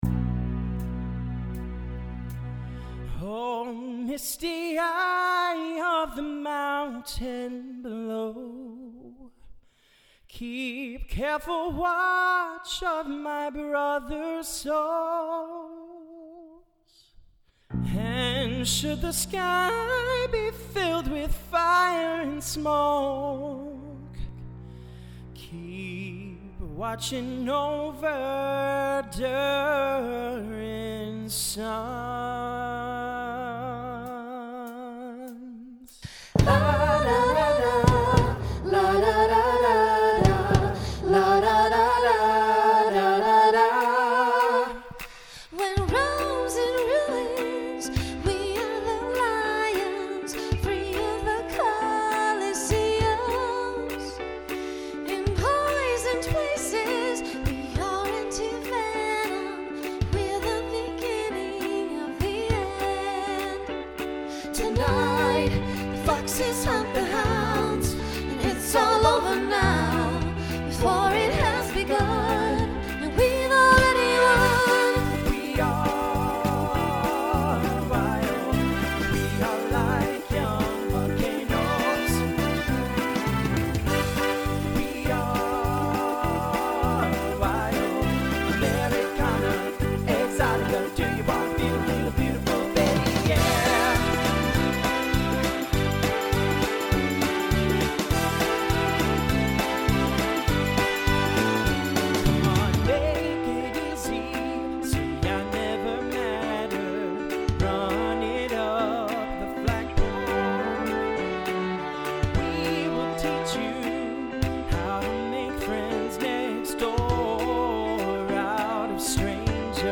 guys/girls feature
Voicing Mixed Instrumental combo Genre Pop/Dance